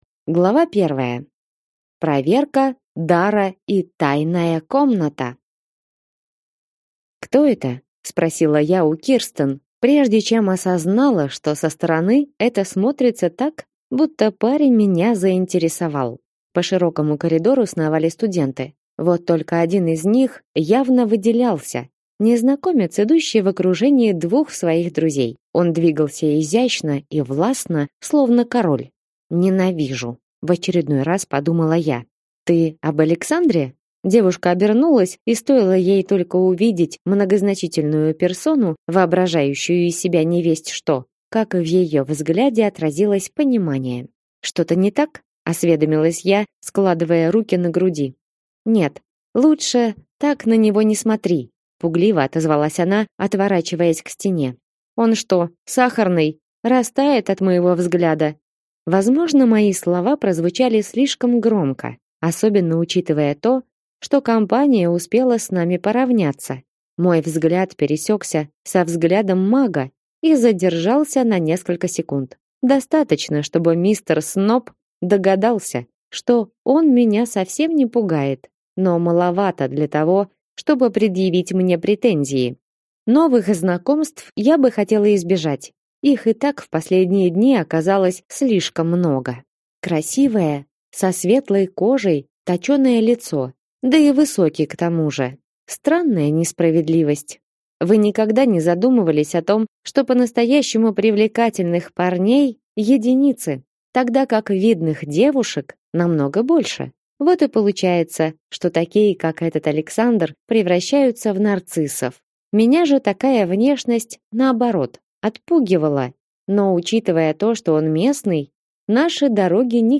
Аудиокнига Академия магии. Притяжение воды и пламени. Книга 1 | Библиотека аудиокниг